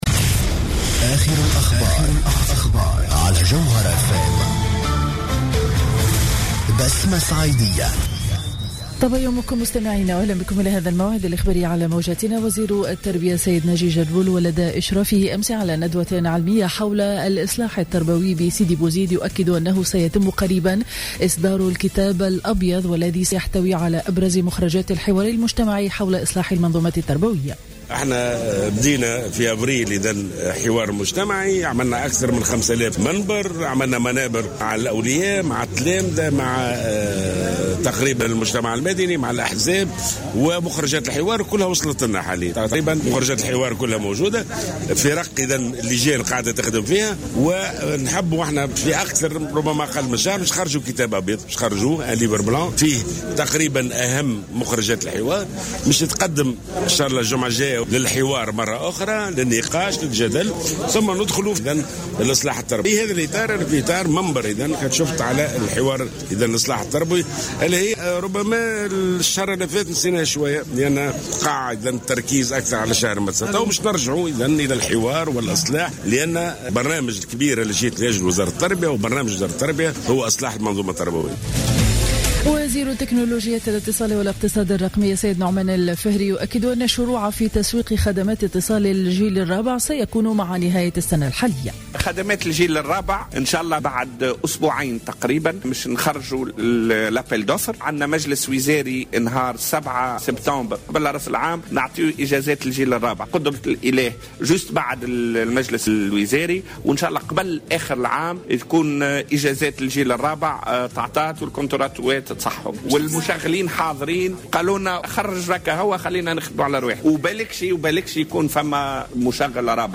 نشرة أخبار السابعة صباحا ليوم الأحد 30 أوت 2015